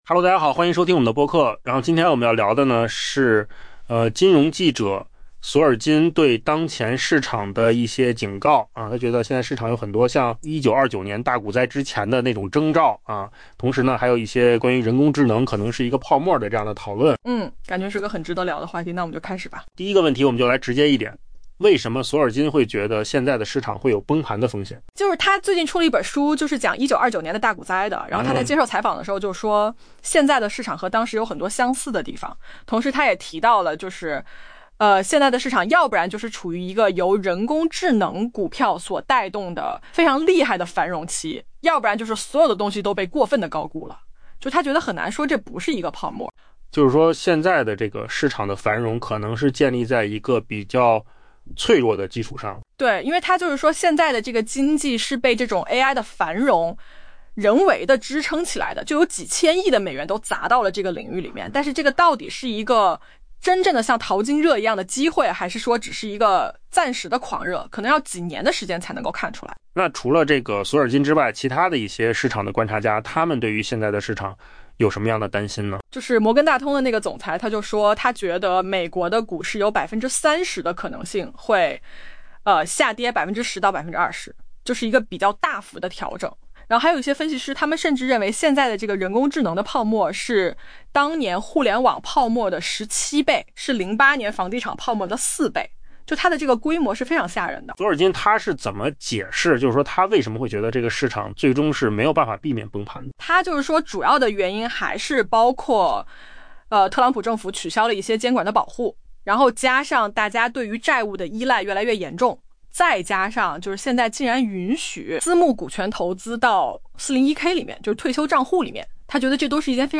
AI 播客：换个方式听新闻 下载 mp3 音频由扣子空间生成 著名金融记者兼作家安德鲁·罗斯·索尔金 （Andrew Ross Sorkin） 发出警告：「我们将会经历一场崩盘。」